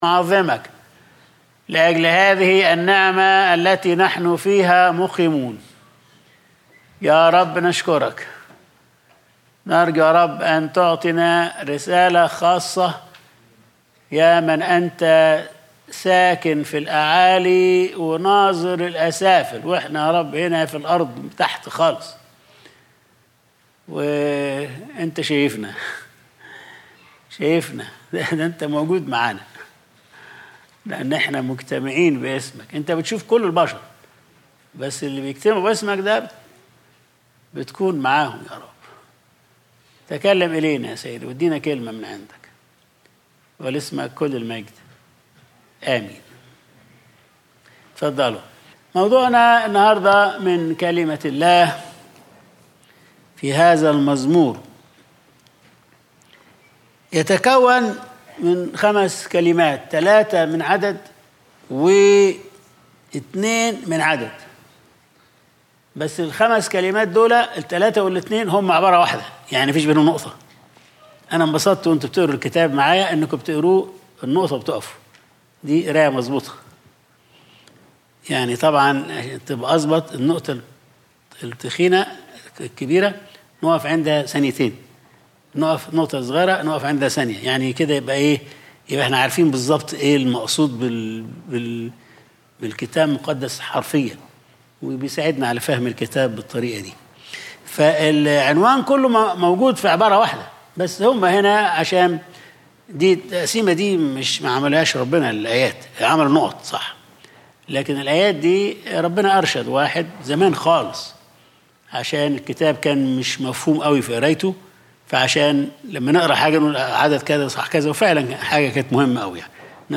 Sunday Service | الساكِن الأعالي الناظر الأسافل